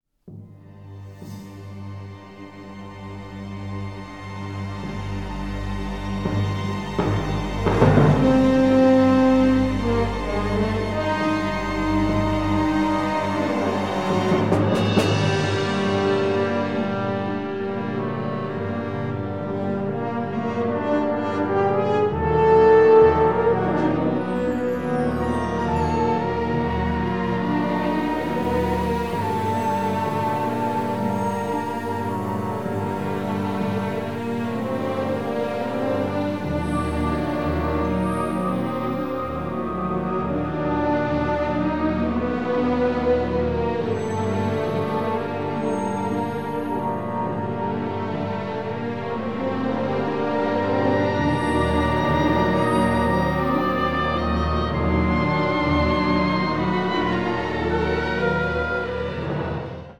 emotional, symphonic Americana score